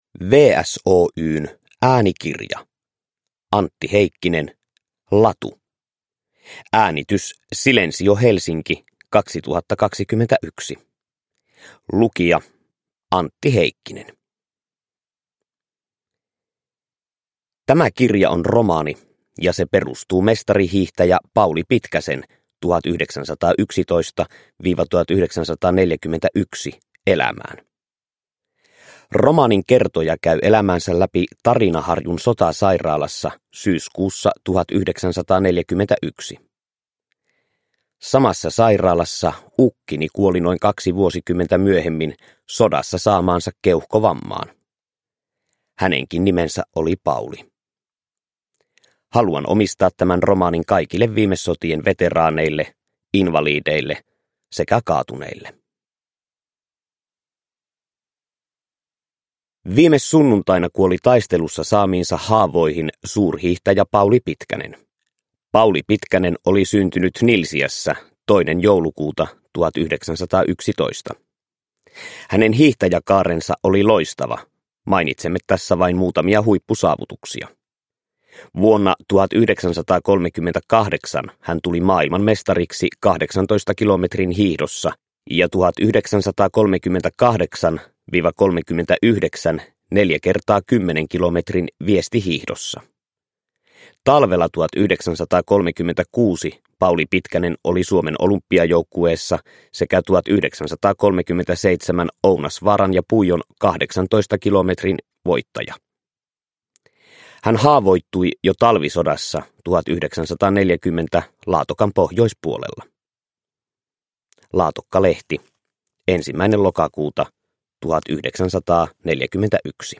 Latu – Ljudbok – Laddas ner